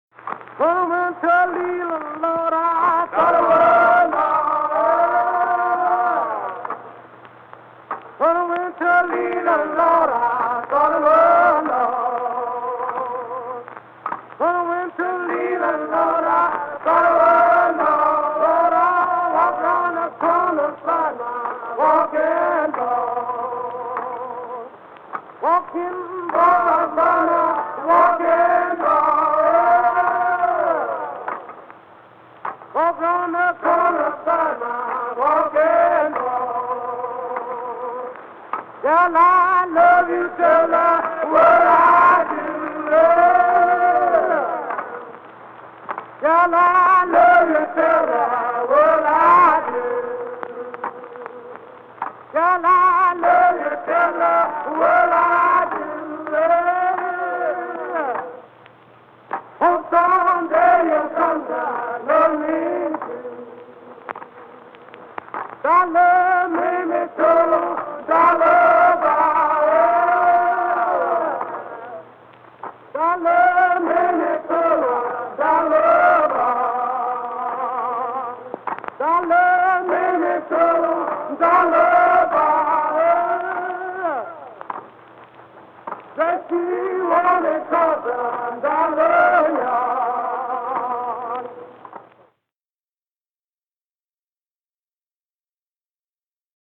Field recordings paired with these images were recorded in rural Mississippi by John and Alan Lomax between 1934 and 1942.